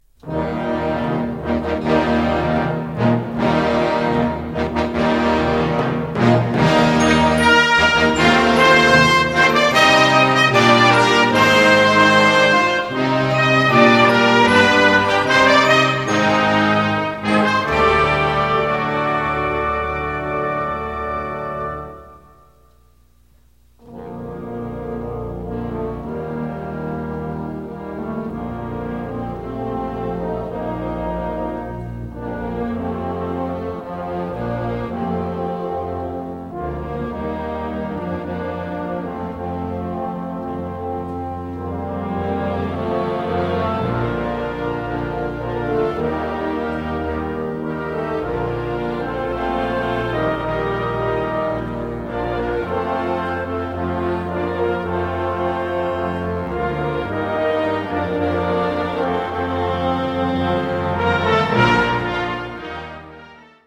Gattung: Young Band
Besetzung: Blasorchester